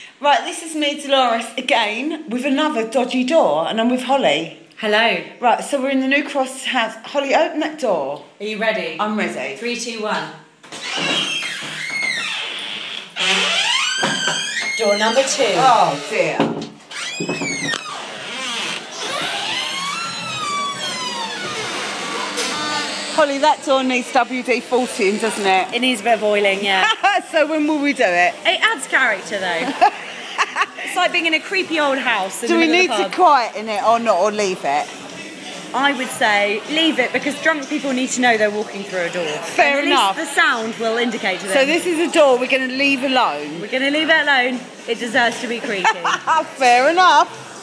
Another screaming door, The new Cross House